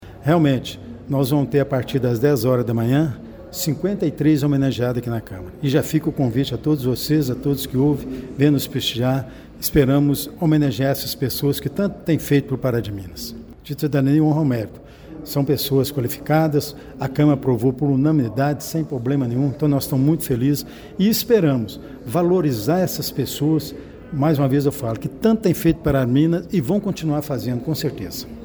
O presidente da Mesa Diretora, Délio Alves Ferreira (PL), cita que as homenagens aprovadas pelos vereadores são muito justas as pessoas que tanto fazem pelo progresso do município: